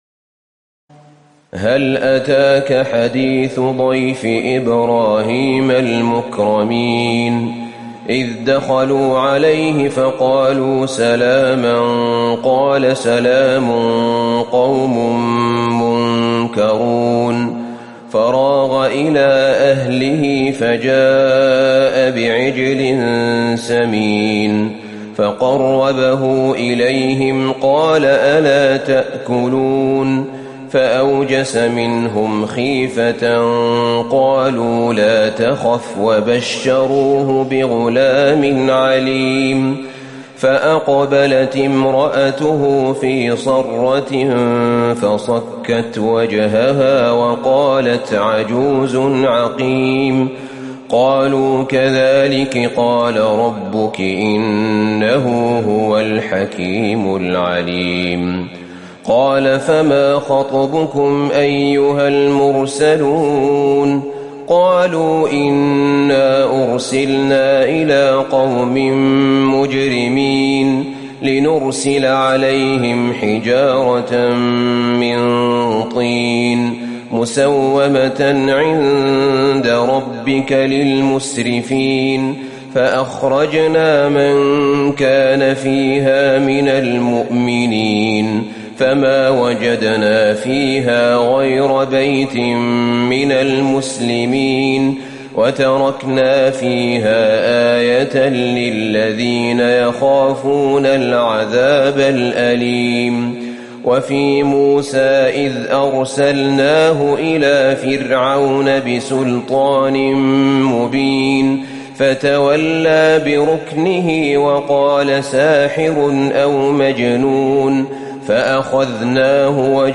تراويح ليلة 26 رمضان 1438هـ من سور الذاريات(24-60) و الطور و النجم و القمر Taraweeh 26 st night Ramadan 1438H from Surah Adh-Dhaariyat and At-Tur and An-Najm and Al-Qamar > تراويح الحرم النبوي عام 1438 🕌 > التراويح - تلاوات الحرمين